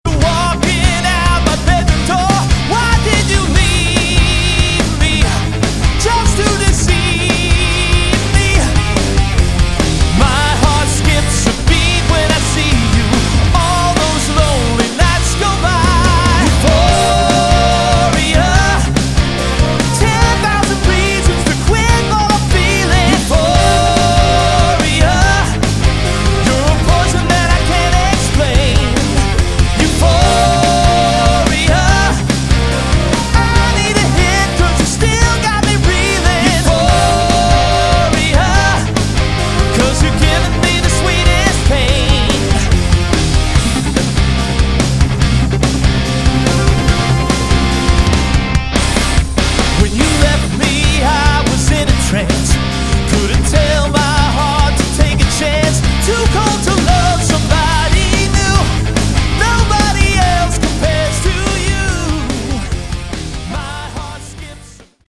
Category: AOR / Melodic Rock
vocals
drums
bass
guitars
keyboards